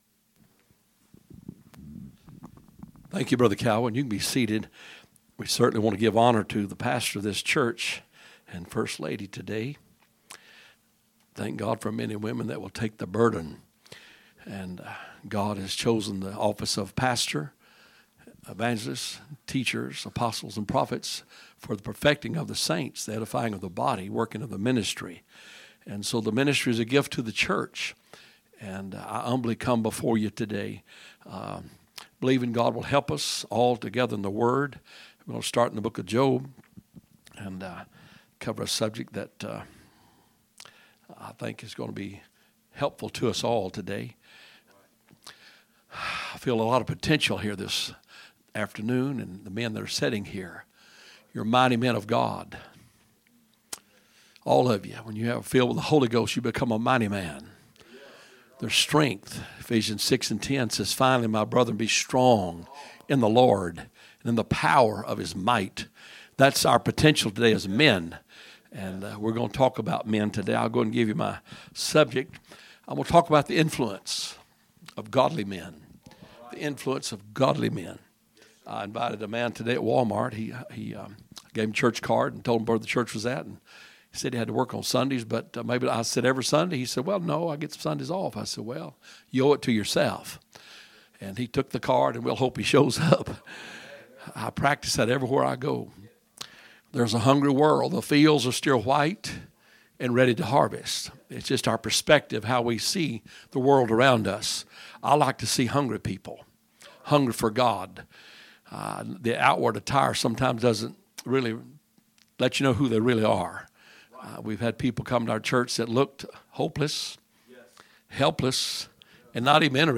A message from the series "Guest Speakers." Saturday Message